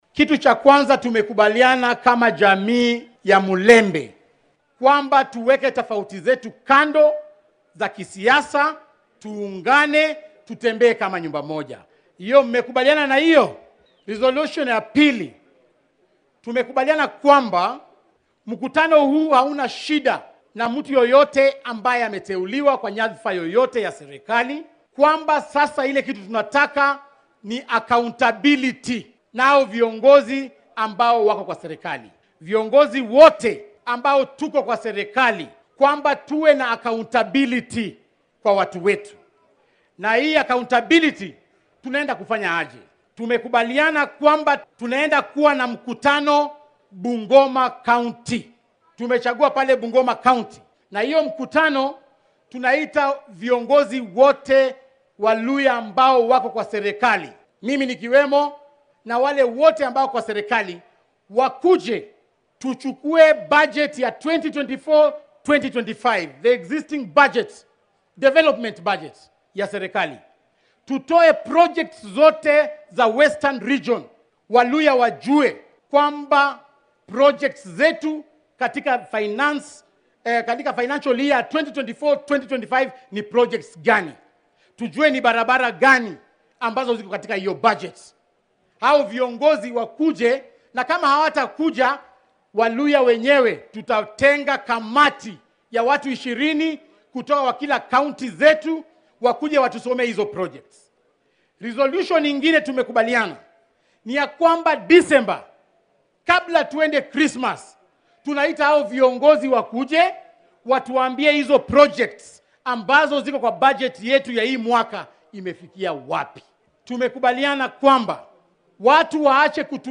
War-murtiyeedka ka soo baxay kulankaasi oo ka kooban qodobbo dhowr ah waxaa warbaahinta u akhriyay xoghayaha guud ee xisbiga UDA, Cleophas Malala.